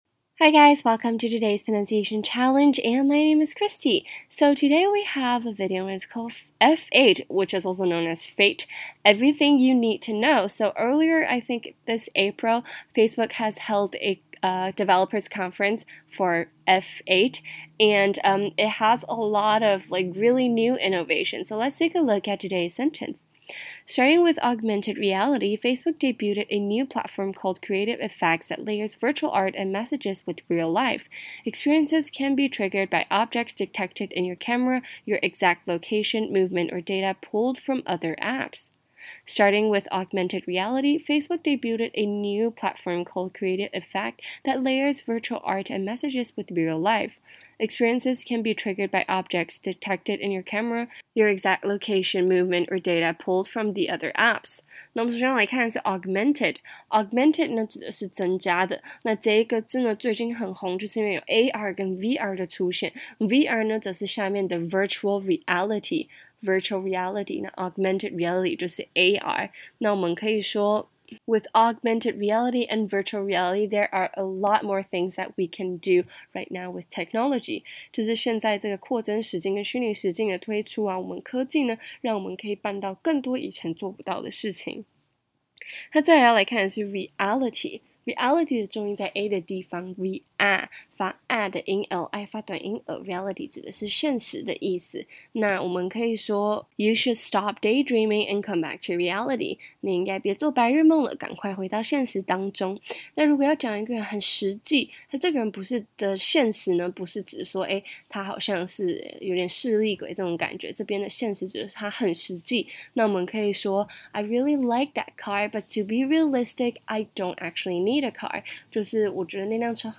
台湾籍旅美老师今天交给我们的句子是：